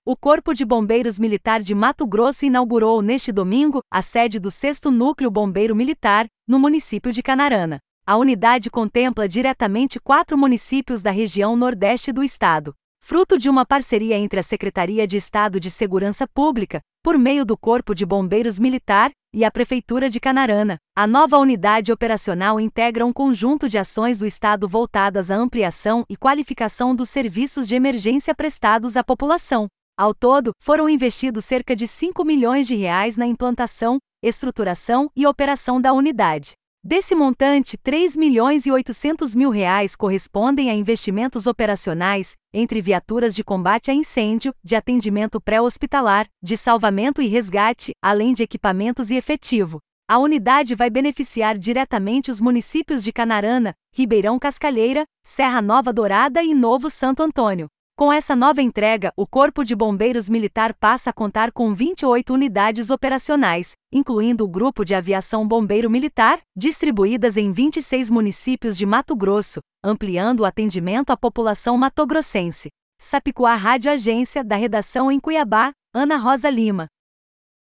Boletins de MT 16 fev, 2026